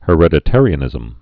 (hə-rĕdĭ-târē-ə-nĭzəm)